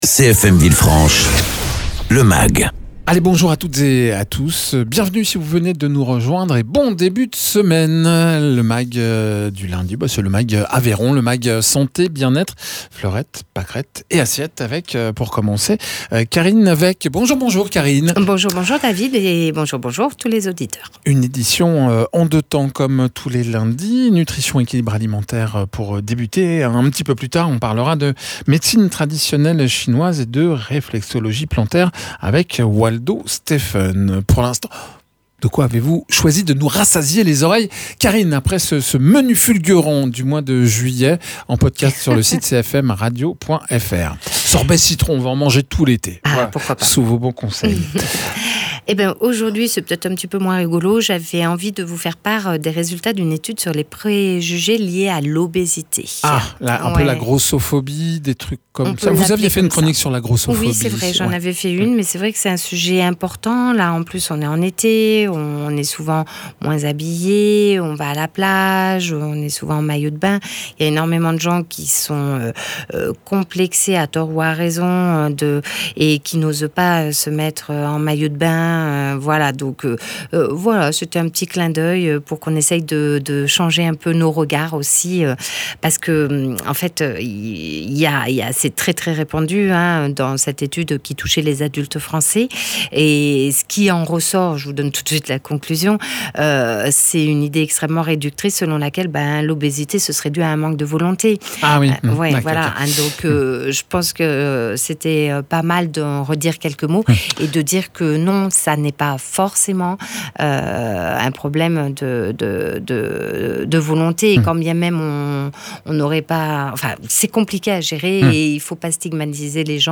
nutritionniste diététicienne
praticien en réflexologie plantaire et Médecine Traditionnelle Chinoise